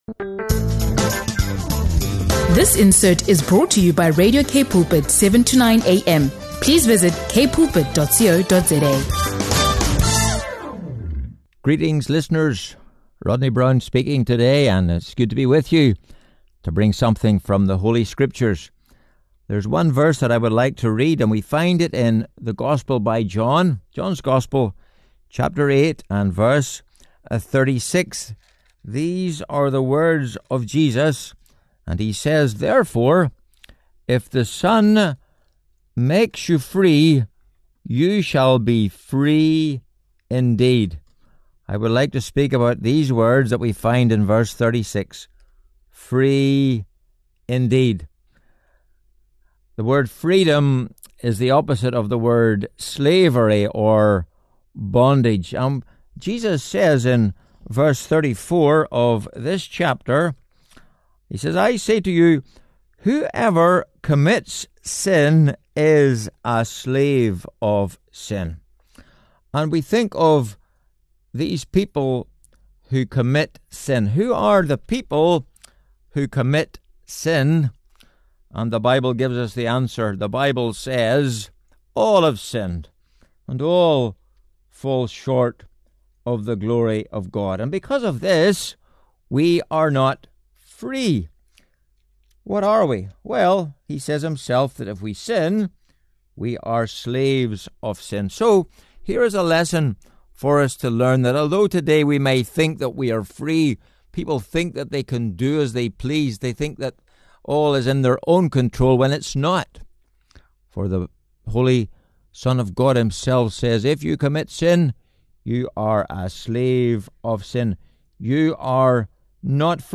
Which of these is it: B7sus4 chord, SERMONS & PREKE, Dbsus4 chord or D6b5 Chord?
SERMONS & PREKE